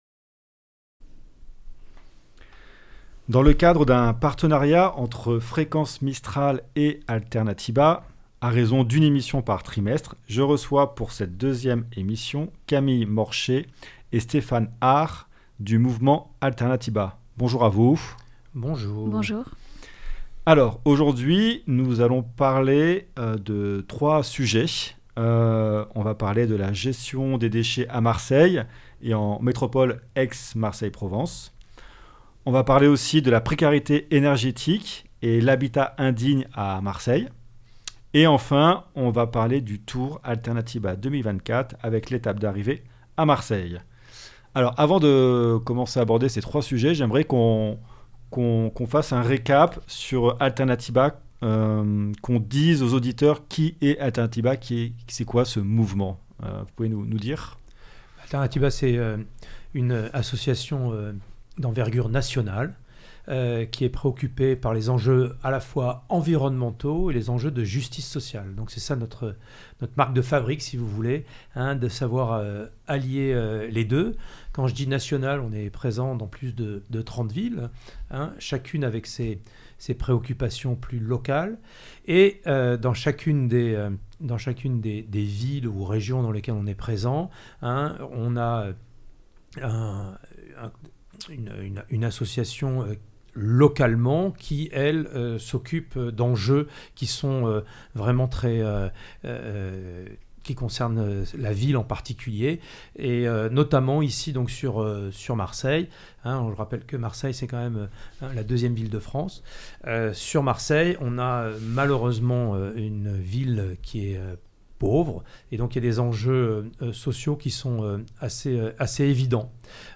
Itw Alternatiba (42.65 Mo)